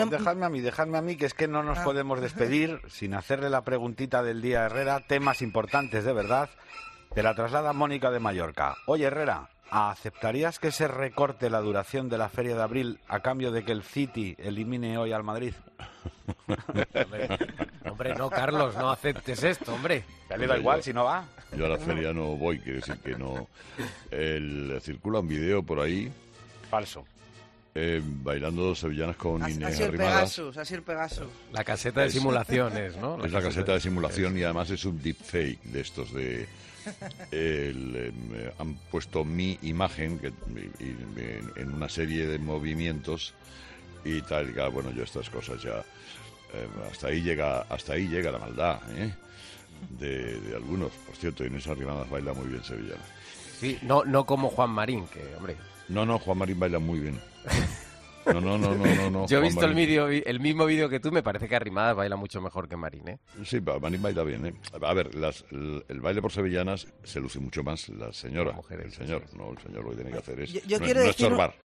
El presentador de 'Herrera en COPE' se excusó alegando que las imágenes, en realidad, se habrían tomado en la famosísima caseta de simulación de Sevilla